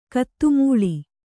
♪ kaattumūḷi